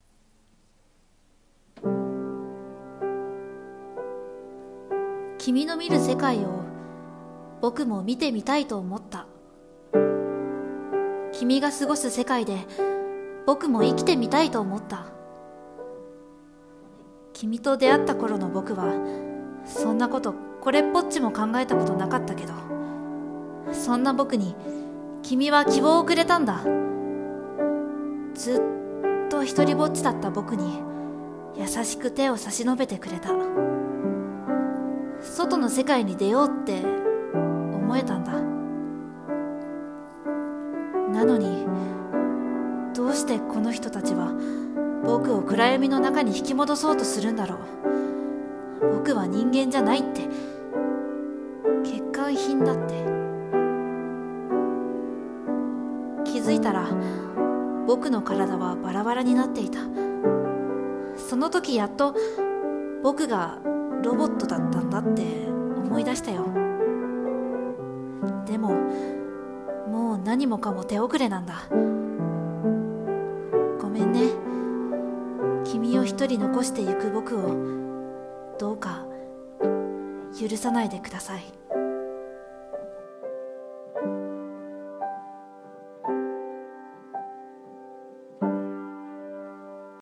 ［声劇台本］人間に恋したロボットの話